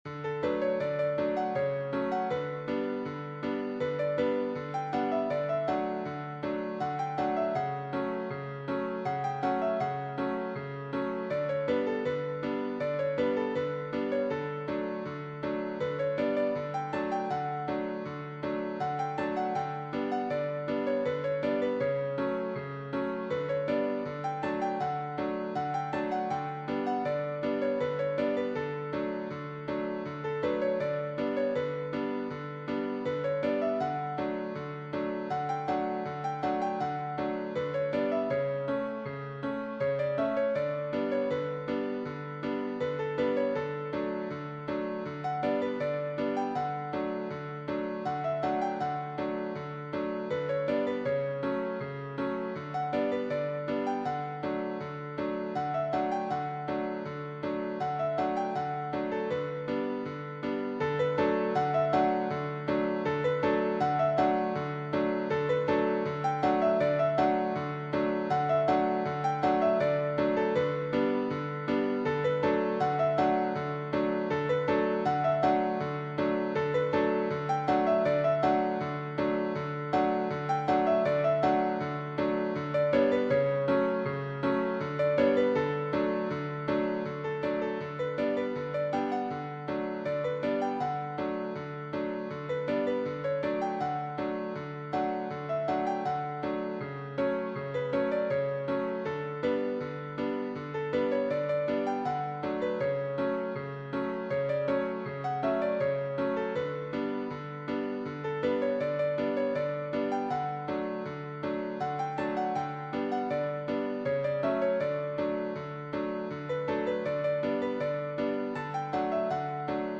soft rhythms of gondolas swaying in the marina
Romantic
Piano only